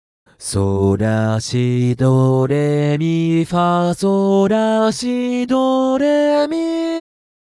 【説明】 ： 強すぎず弱すぎない音源です。